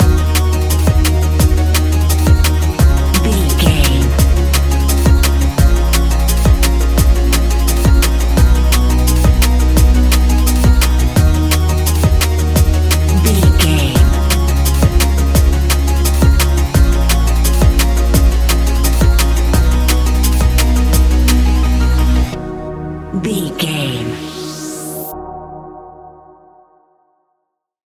Ionian/Major
D♯
electronic
techno
trance
synths
synthwave